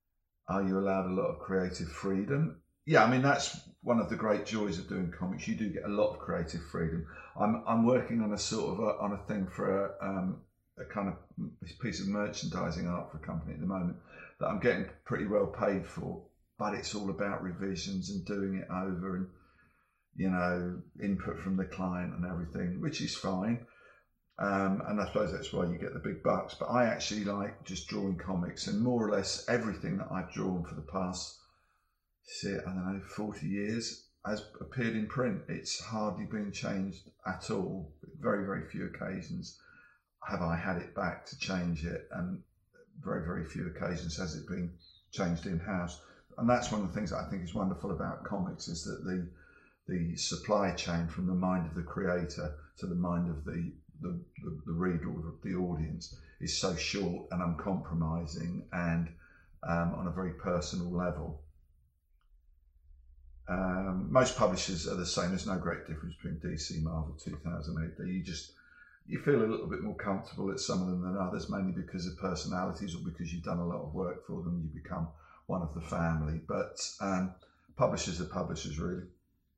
Dave Gibbons interview: Do publishers offer a lot of creative freedom?